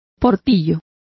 Complete with pronunciation of the translation of wickets.